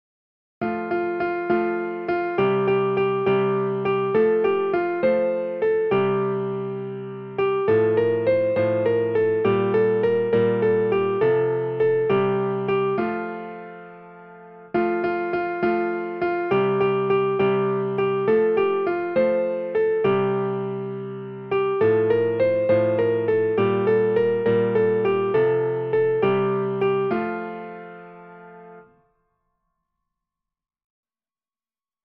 Nursery Rhymes:
for piano